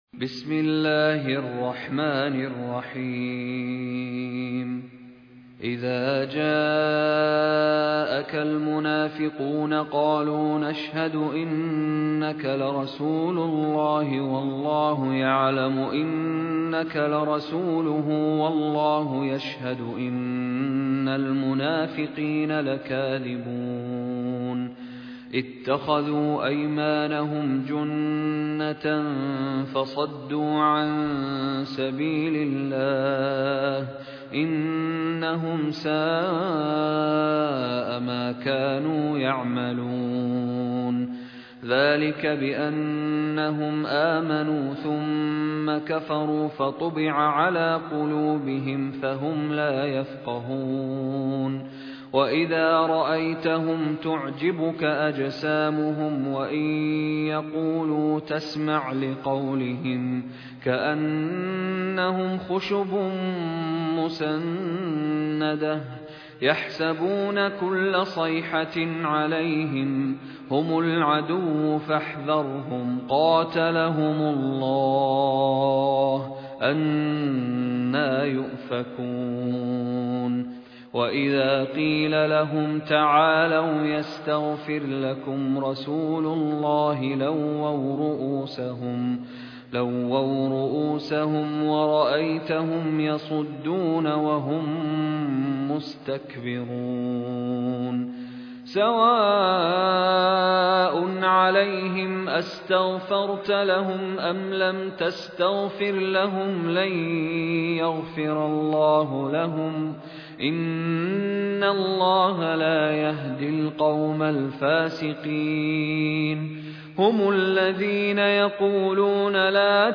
مشاري بن راشد العفاسي المصحف المرتل - حفص عن عاصم - المنافقون